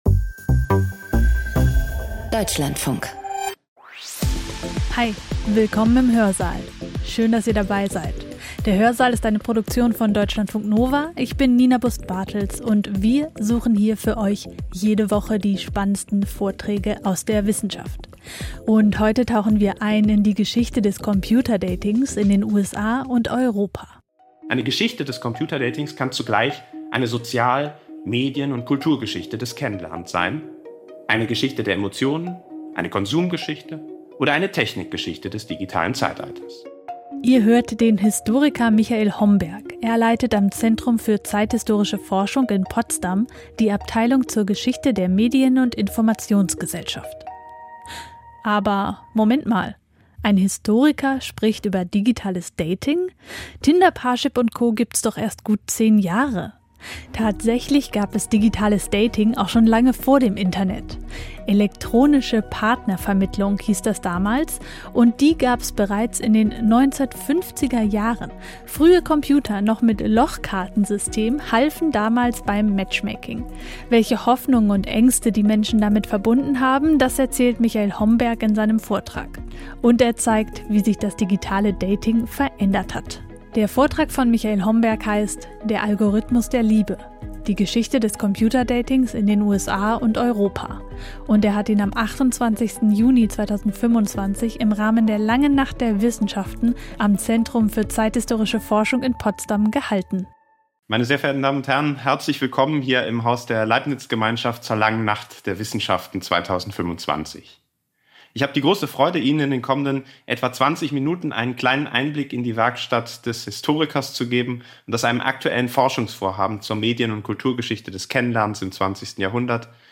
Ein Vortrag